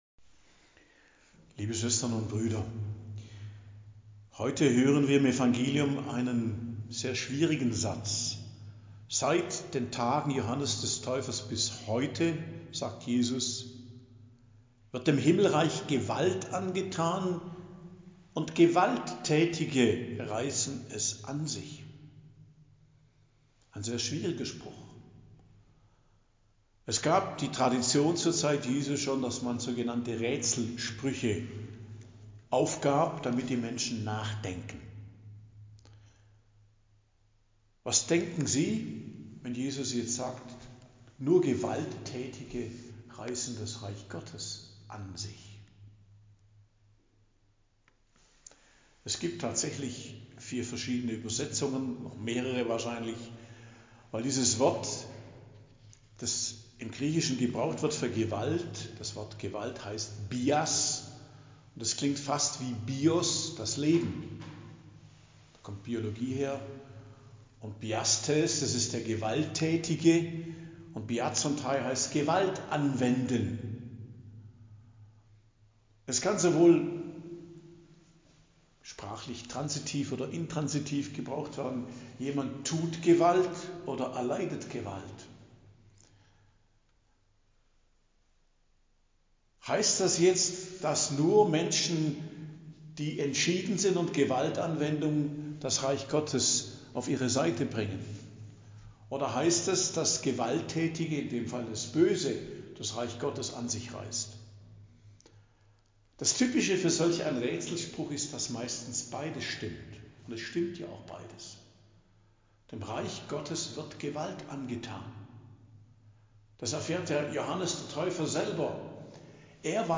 Predigt am Donnerstag der 2. Woche im Advent, 12.12.2024